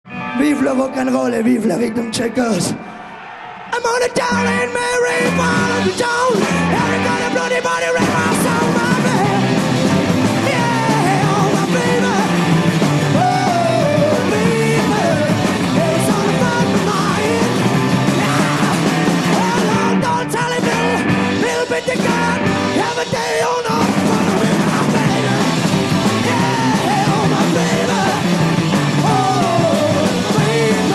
A l'Olympia
Rock'n'roll garage